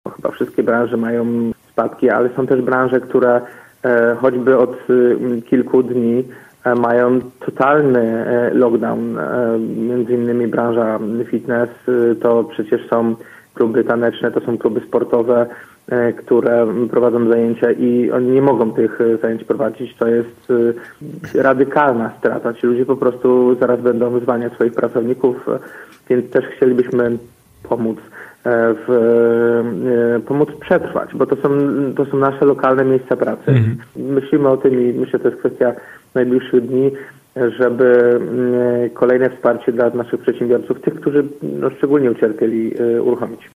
Jak mówił w Radiu Gorzów prezydent Jacek Wójcicki, trwają prace nad podobnym rozwiązaniem: